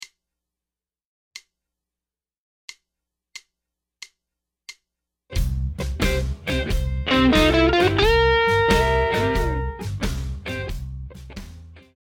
Blues Lick 5